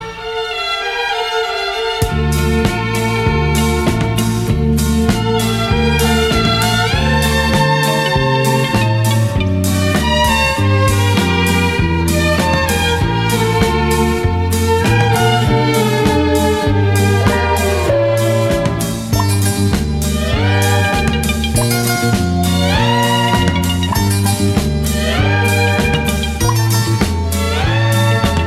• Качество: 192, Stereo
скрипка
(соло - скрипки)